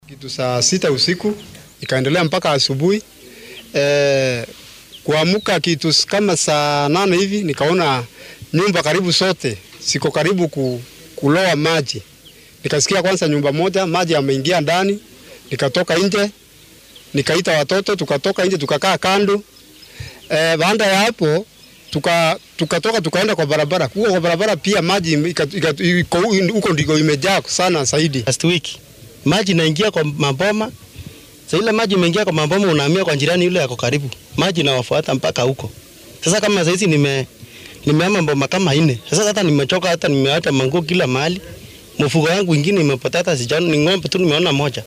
Qaar ka mid ah shacabka deegaankaasi ayaa warbaahinta uga warramay saameynta ka soo gaartay daadadka.